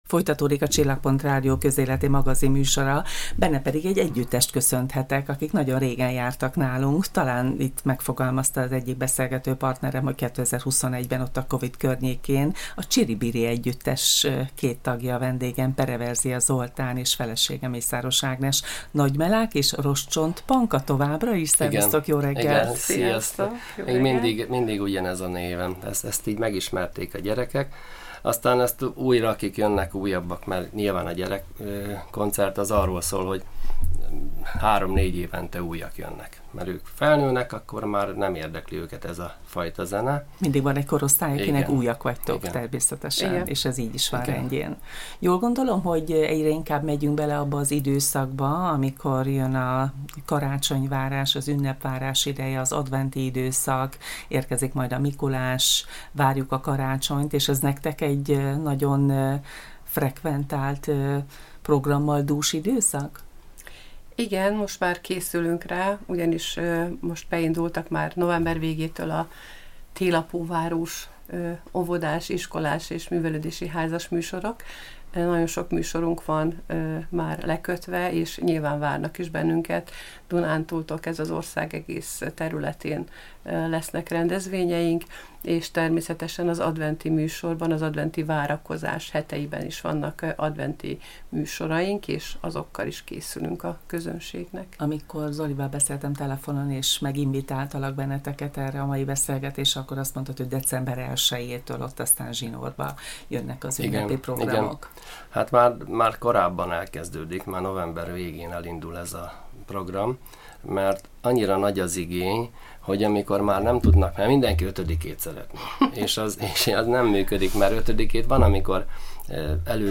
A közelmúltban két jótékonysági koncerttel is támogatták a GYEK Onkohematológiai és Csontvelőtranszplantációs Osztályát, miközben már gőzerővel készülnek a Mikulás és karácsonyi fellépéseikre. A beszélgetés végén felcsendült állandó slágerük, az Oviszerelem.